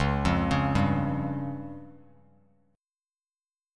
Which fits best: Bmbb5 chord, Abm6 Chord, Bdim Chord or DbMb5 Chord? DbMb5 Chord